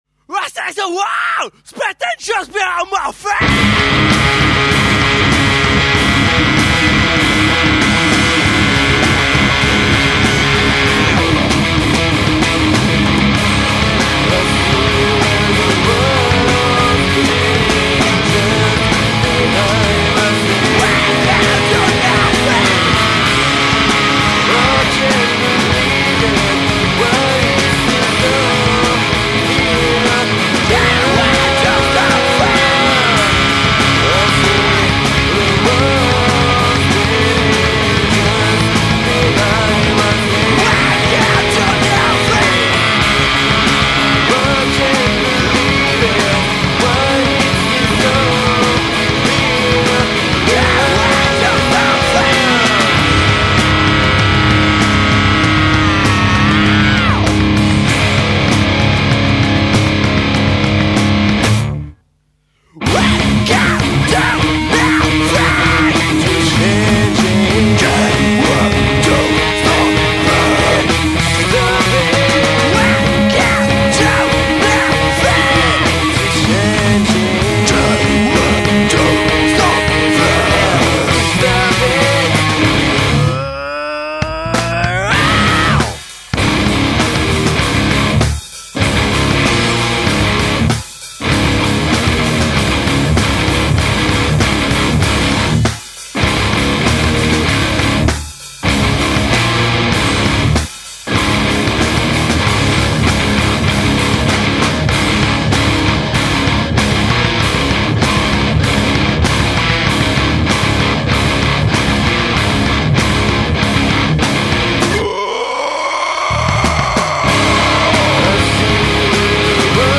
Style : Metal-Emocore